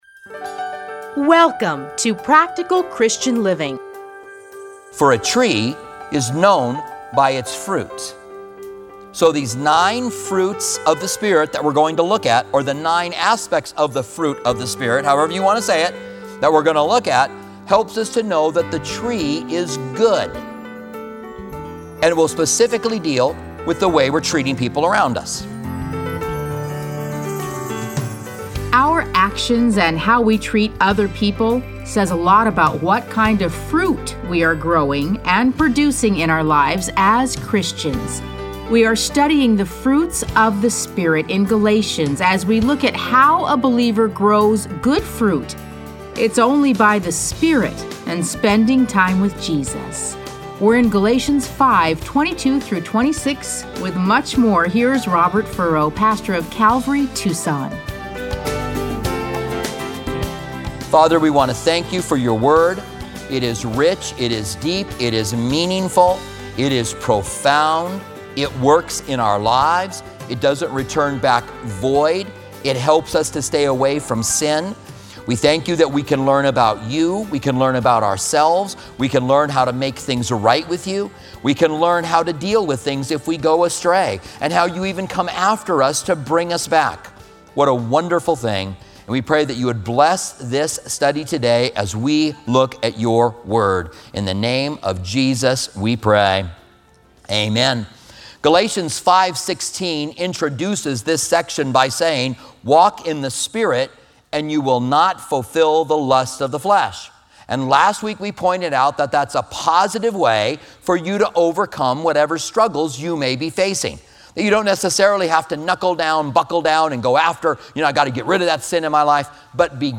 Listen to a teaching from Galatians 5:22-26.